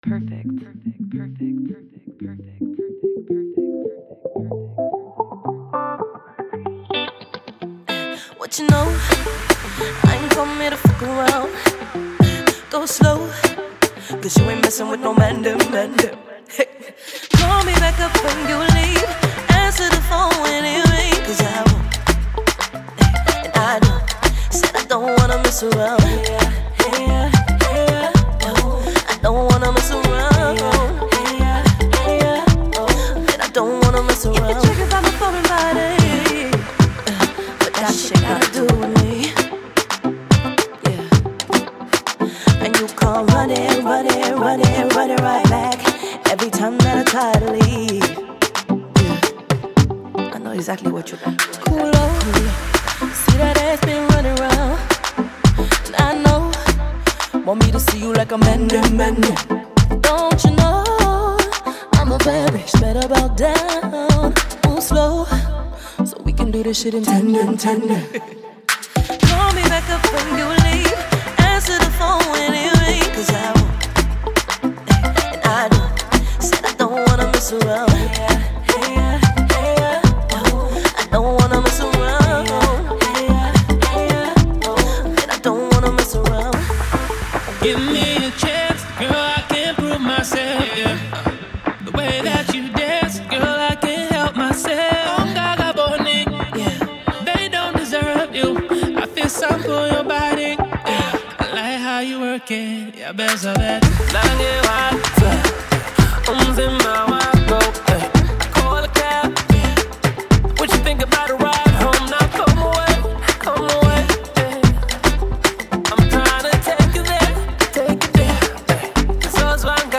Afrobeat, New Jack Swing, and Deep House tracks